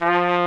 Index of /90_sSampleCDs/Roland L-CD702/VOL-2/BRS_Tpt Cheese/BRS_Cheese Tpt